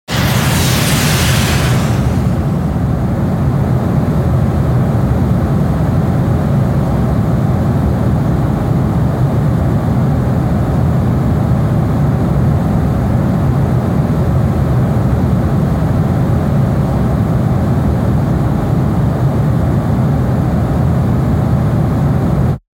دانلود آهنگ باد 72 از افکت صوتی طبیعت و محیط
دانلود صدای باد 72 از ساعد نیوز با لینک مستقیم و کیفیت بالا
جلوه های صوتی